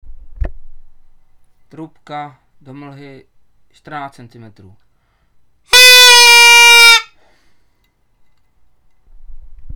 Mosazná signální trubka do mlhy, 14 cm.